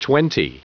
Prononciation du mot twenty en anglais (fichier audio)
Prononciation du mot : twenty